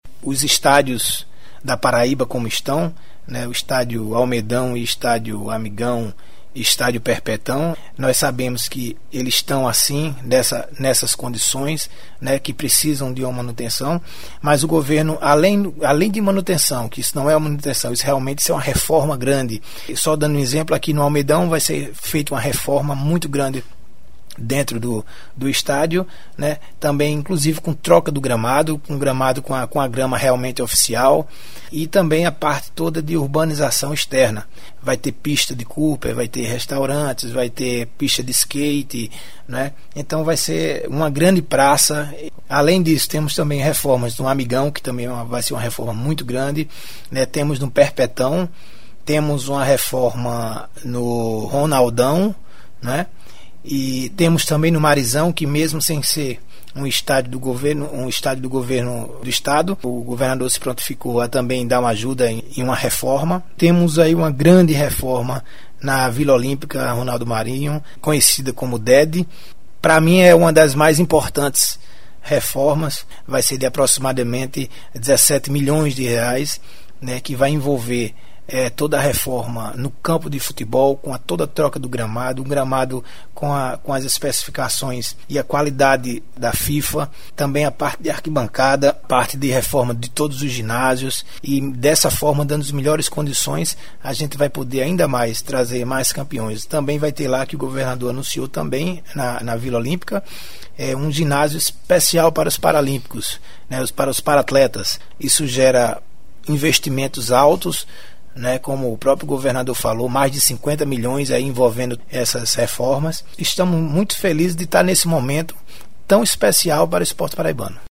Acompanhe clicando nos links em anexo sonoras de entrevista produzida no estúdio da Secretaria de Estado da Comunicação Institucional com o secretário da Juventude, Esporte e Lazer, José Marco.